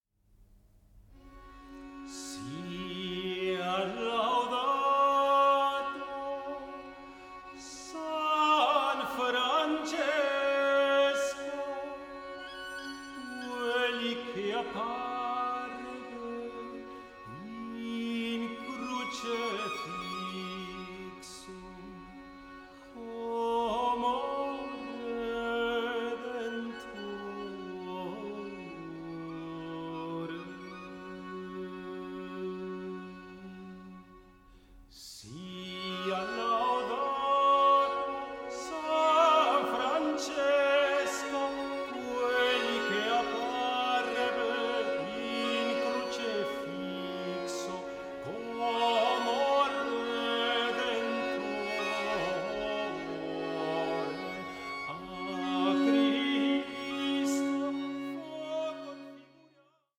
BAROQUE MEETS THE ORIENT: MUSIC AS INTERCULTURAL DIALOGUE